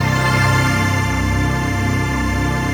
DM PAD2-04.wav